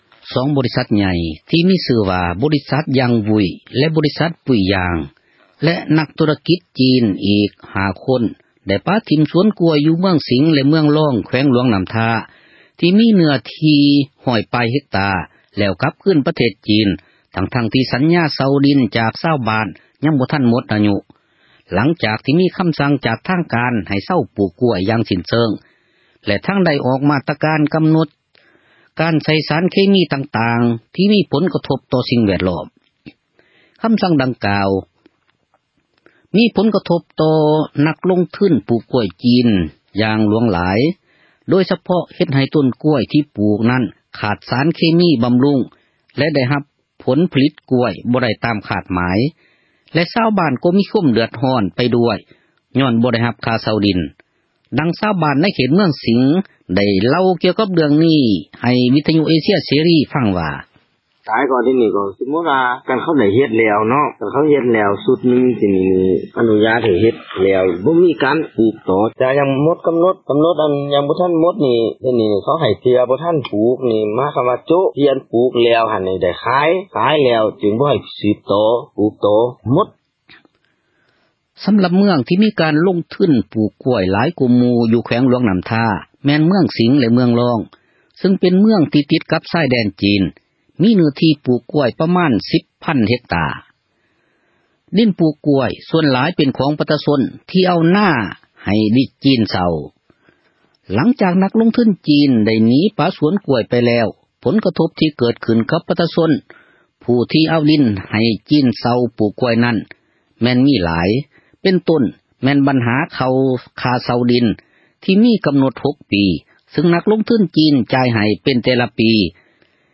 ຄຳສັ່ງດັ່ງກ່າວ ມີຜົນກະທົບ ຕໍ່ນັກລົງທຶນ ປູກກ້ວຍ ຢ່າງຫຼວງຫຼາຍ ໂດຍສະເພາະ ເຮັດໃຫ້ ຕົ້ນກ້ວຍ ທີ່ປູກນັ້ນ ຂາດສານເຄມີ ບຳລຸງ ເຮັດໃຫ້ ຜົລຜລິດກ້ວຍ ບໍ່ໄດ້ຕາມ ຄາດໝາຍ ແລະຊາວບ້ານ ກໍຕ້ອງເດືອດຮ້ອນ ໄປນໍາດ້ວຍ ຍ້ອນບໍ່ໄດ້ ຄ່າເຊົ່າທີ່ດິນ. ດັ່ງຊາວບ້ານ ຢູ່ເມືອງສິງ ໄດ້ເລົ່າໃຫ້ RFA ຟັງວ່າ: